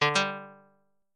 Pickup Sound
beep bloop buttonsound ding gamesounds get menusound short sound effect free sound royalty free Sound Effects